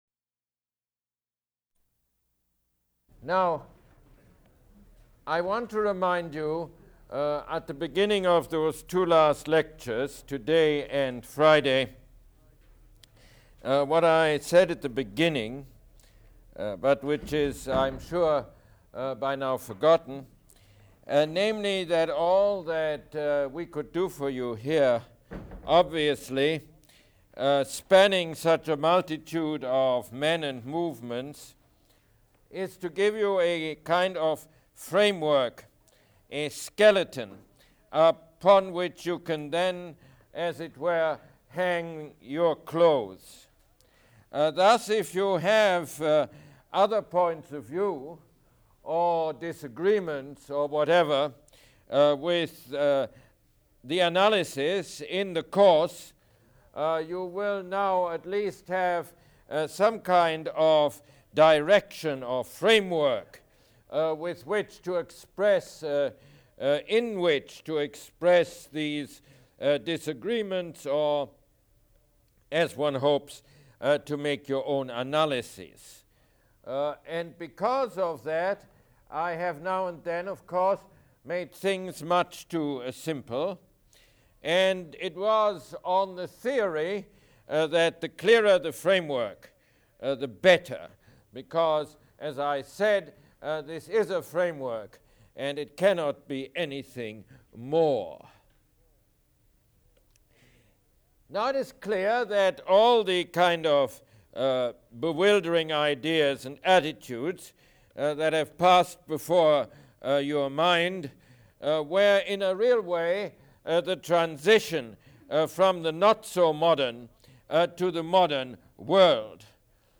Mosse Lecture #36